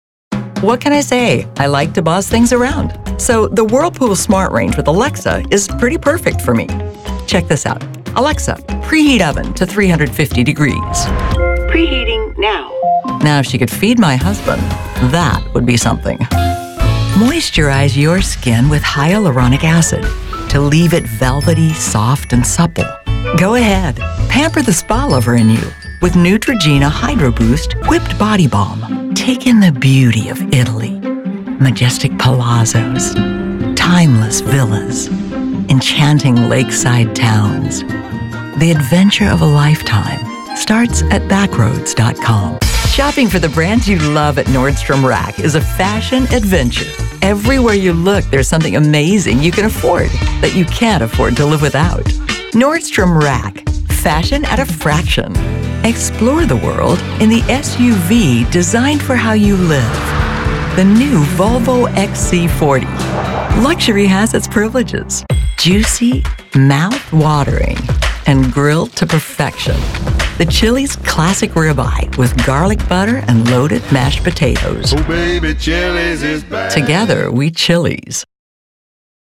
Female Voice Over, Dan Wachs Talent Agency.
Professional, polished, believable
Commercial